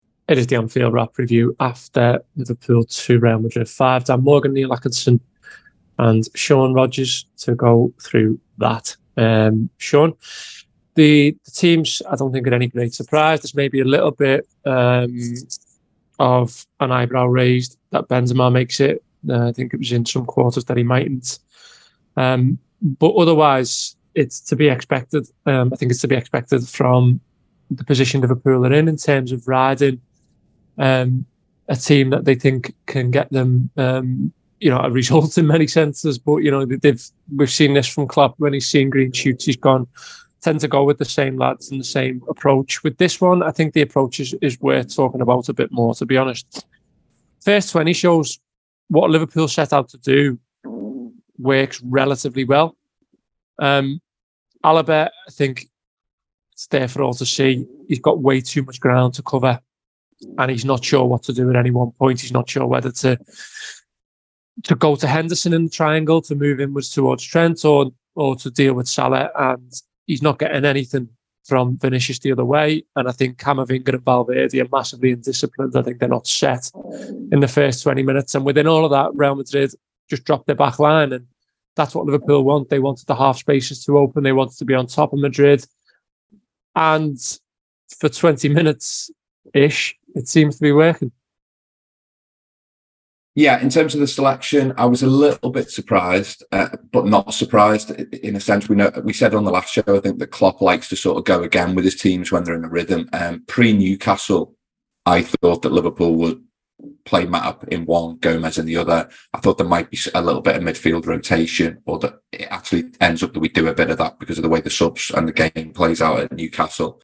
Below is a clip from the show – subscribe for more review chat around Liverpool 2 Real Madrid 5…